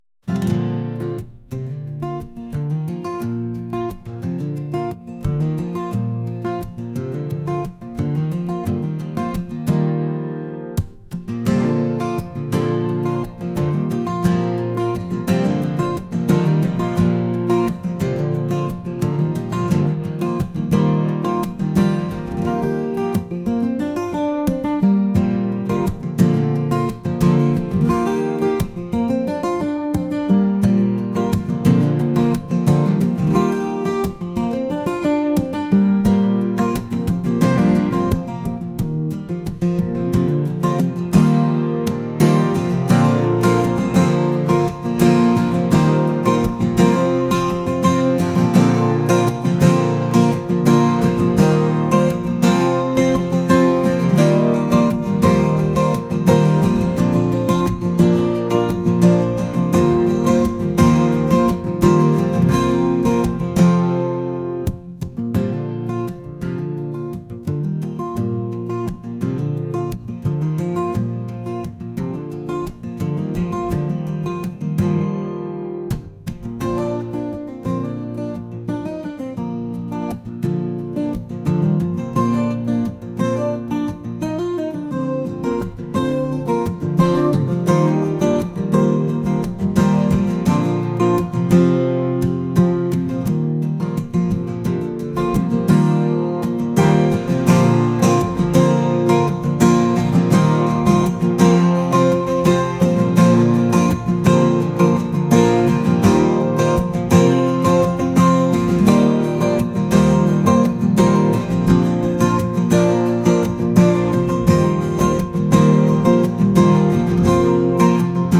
acoustic | folk | ambient